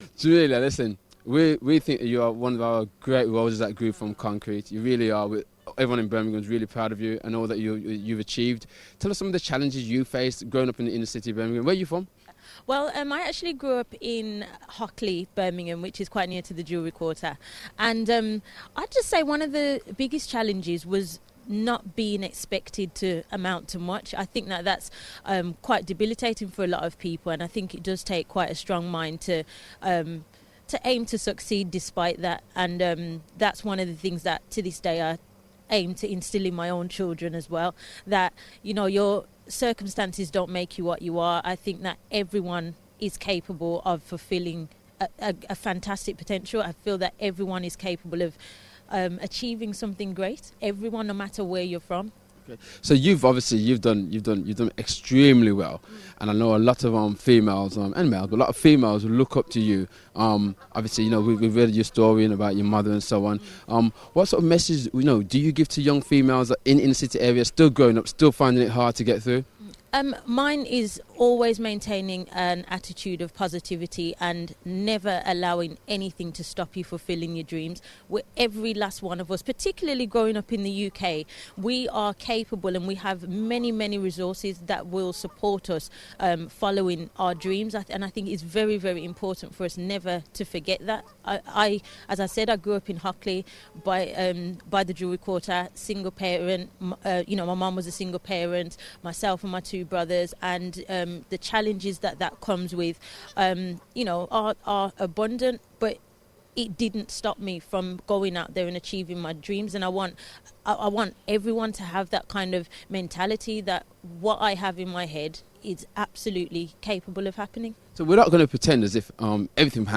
BBC WM Takeover reporter spoke live to Birmingham born stars Jamelia and Cherelle Skeete about growing up in the city...